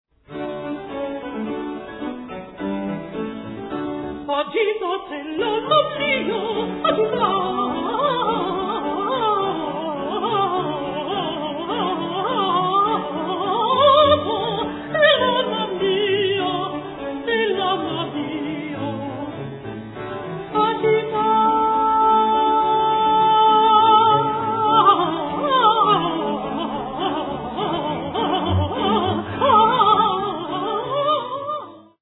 countertenor
oboe
cello